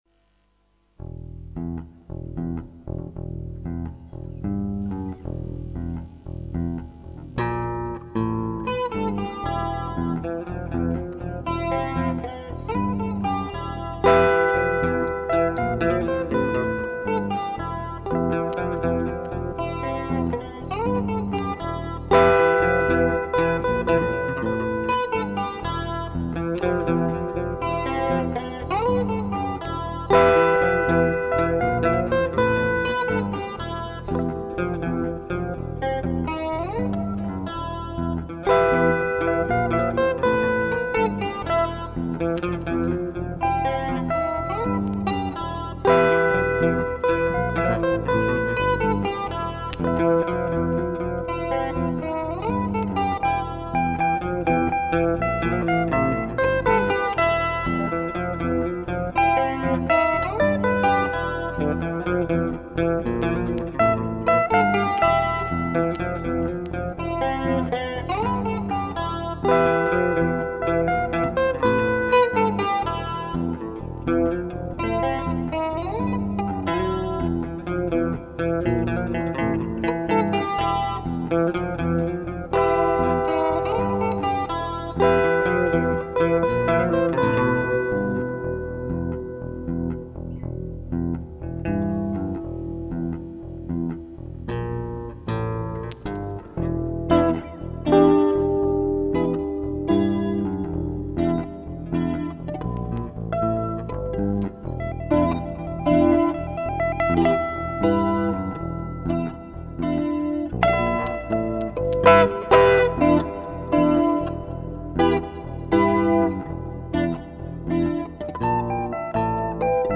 Оригинальная композиция в стиле блюз. Без слов, поэтому огромная просьба оценить произведение в блоге.